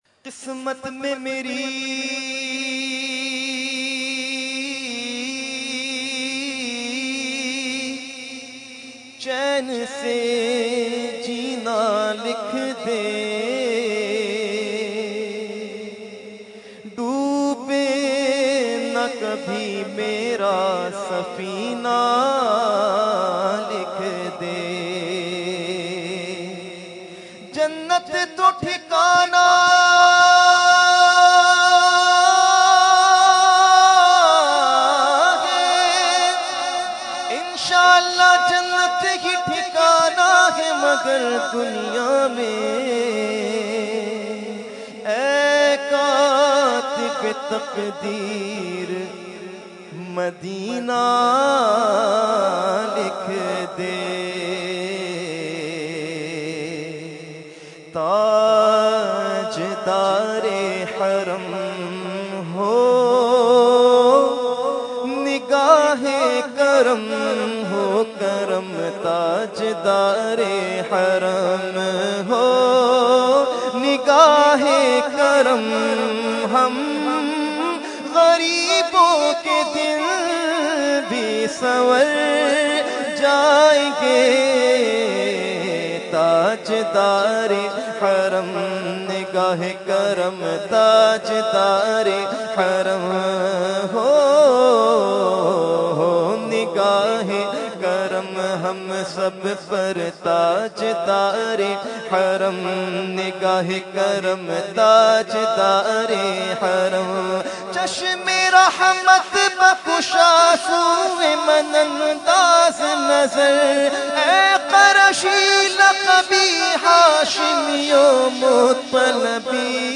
Category : Naat | Language : UrduEvent : Urs Ashraful Mashaikh 2015